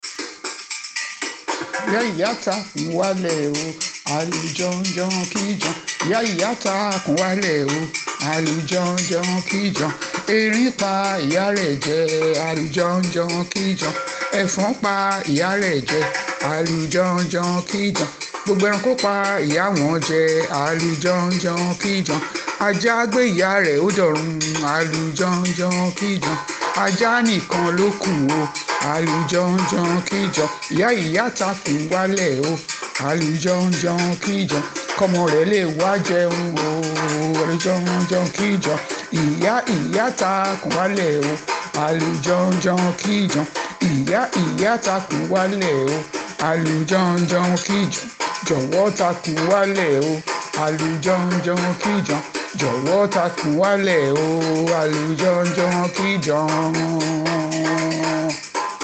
When he got to where his mother was he would start singing: